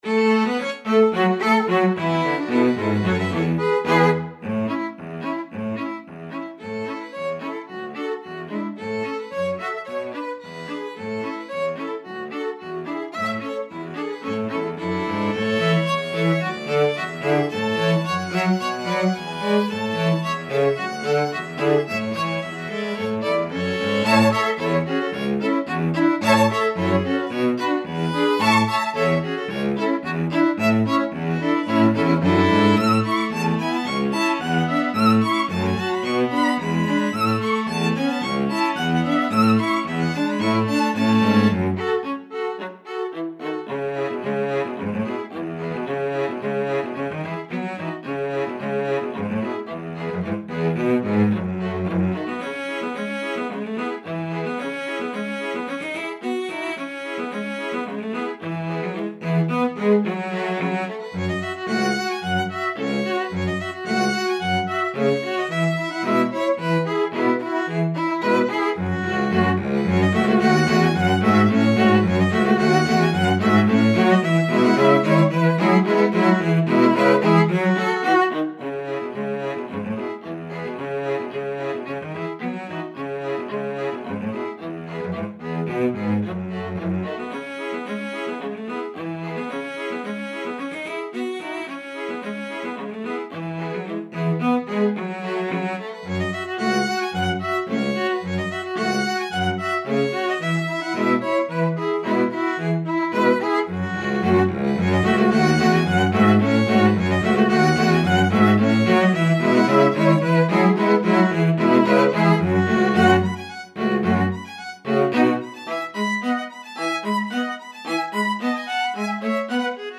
String Quartet version
Violin 1Violin 2ViolaCello
2/4 (View more 2/4 Music)
Moderate polka tempo = 110